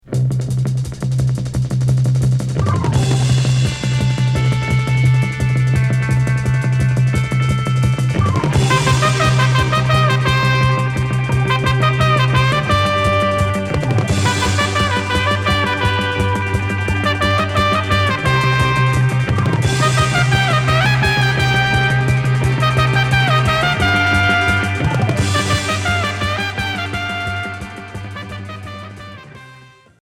Jerk